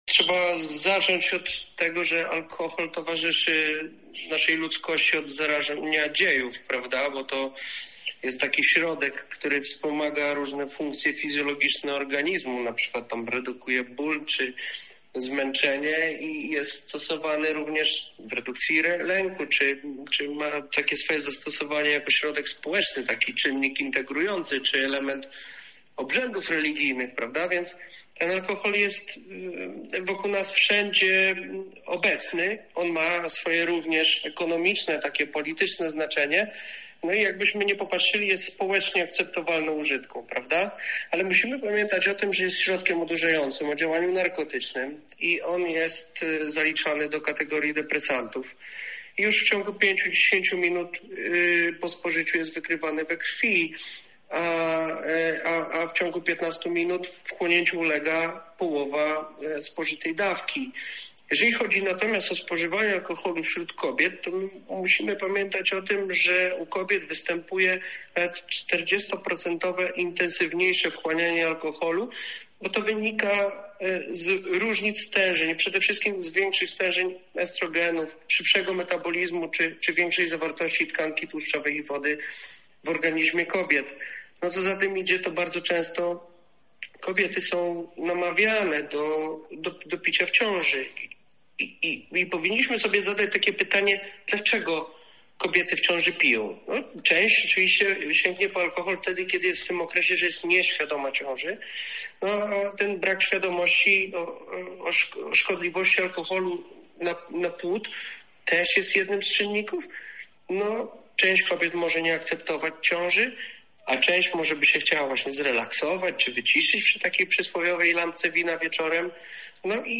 Pełna rozmowa